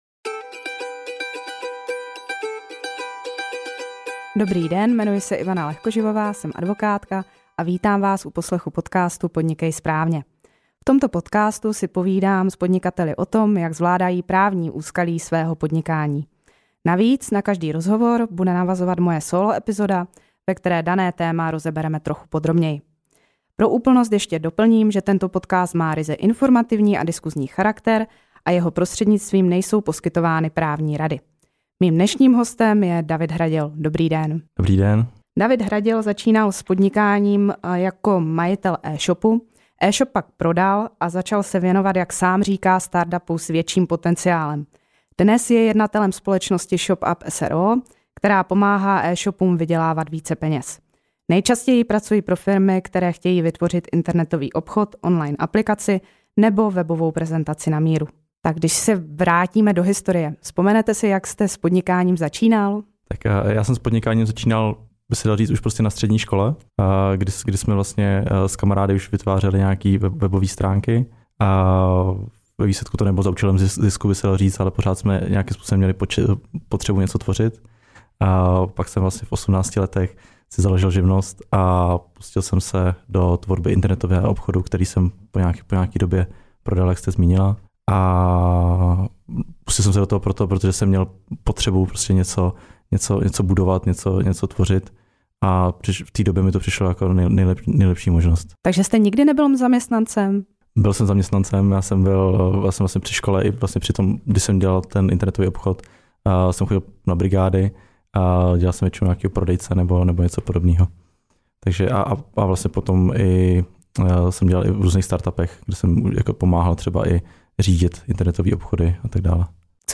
To se dozvíte v tomto rozhovoru.